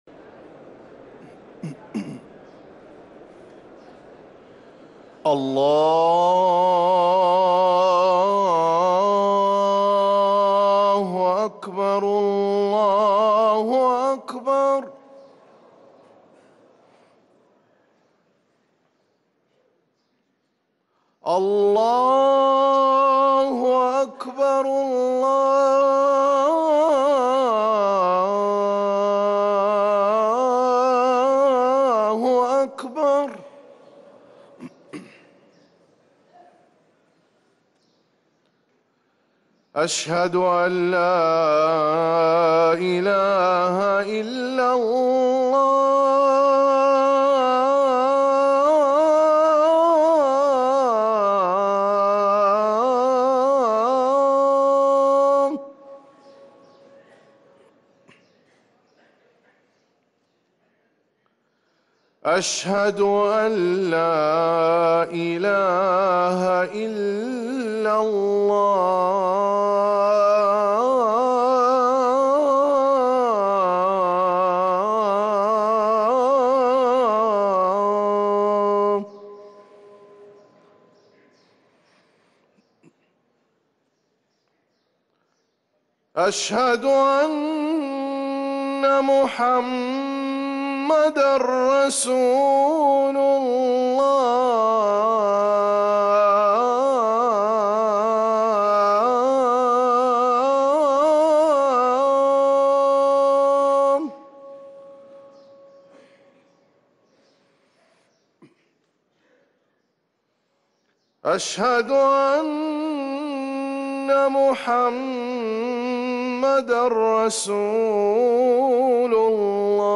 أذان الفجر للمؤذن